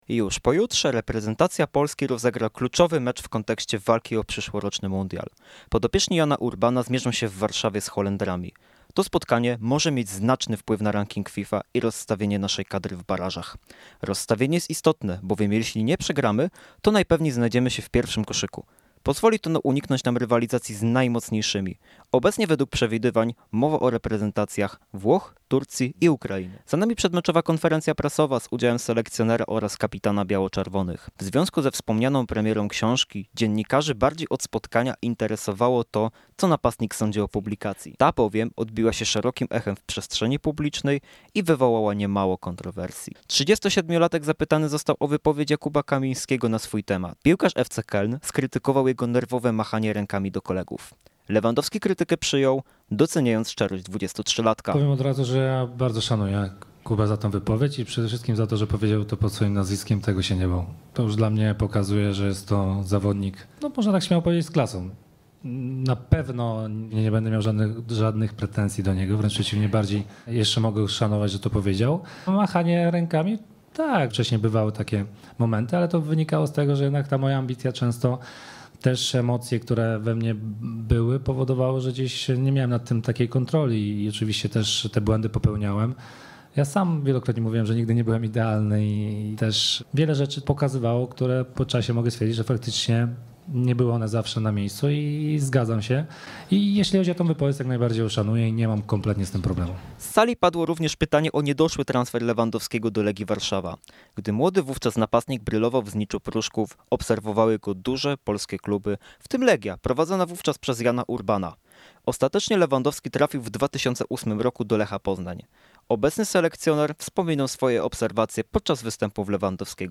Przed meczem miała miejsce konferencja prasowa, w trakcie której napastnik miał okazję odpowiedzieć na palące opinię publiczną pytania. Temat niedoszłego transferu do Legii Warszawa, gdzie miałby szansę na współpracę z aktualnym selekcjonerem polskiej kadry, czy relacja z Kamińskim, to tylko niektóre z tych poruszonych przez dziennikarzy na PGE Narodowym w Warszawie.